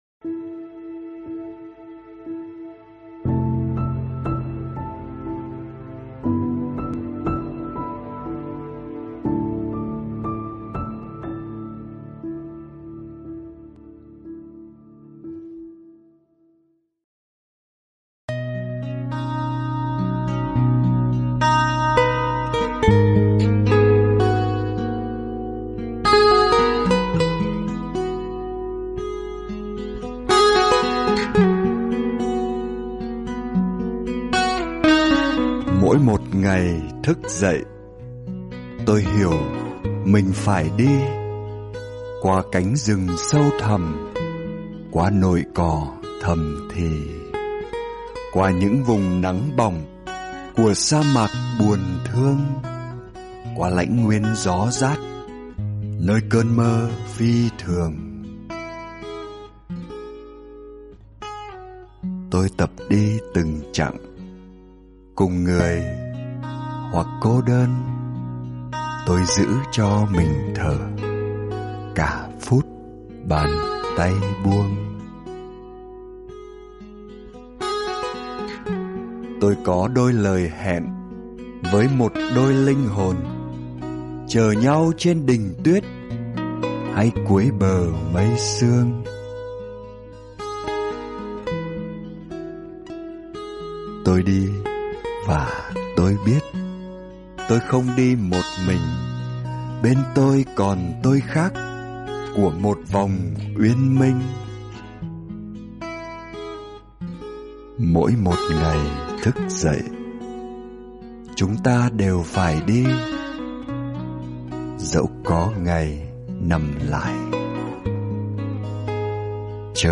Thích Minh Niệm - Mp3 thuyết pháp